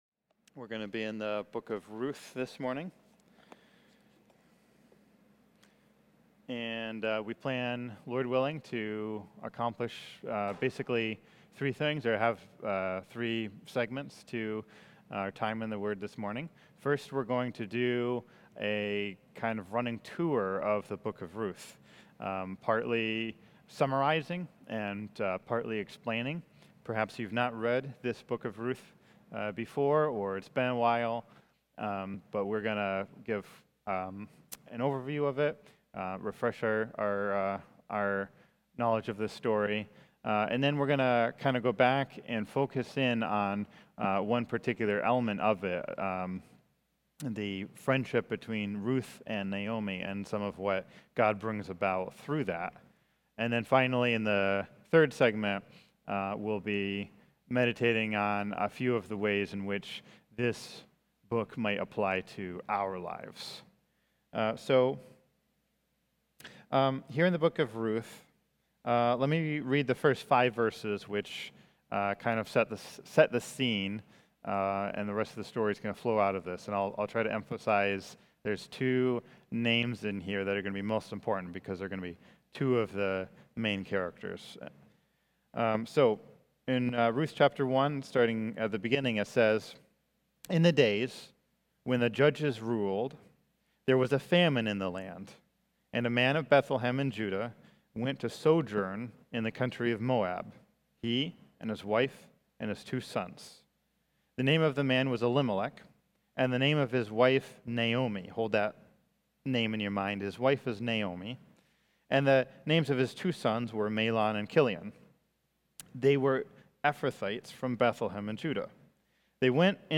Covenant Community Church | West Philadelphia Reformed Charismatic Church
From Series: "Stand Alone Sermons"